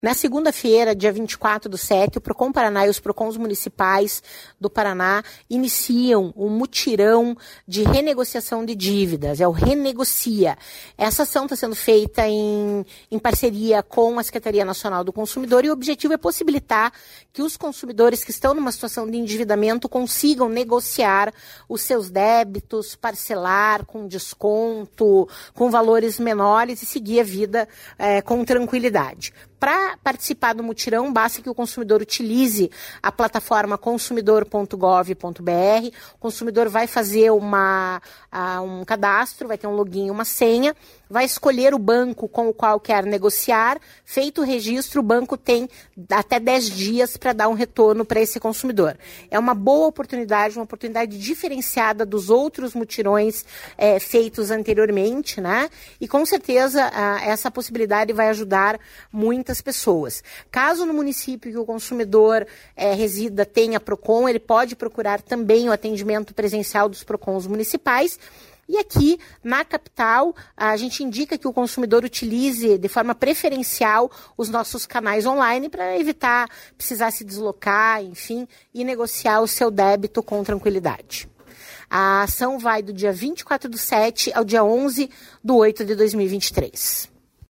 Sonora da coordenadora do Procon, Claudia Silvano, sobre o Mutirão Renegocia! que começa nesta segunda-feira